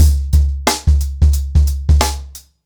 TrackBack-90BPM.53.wav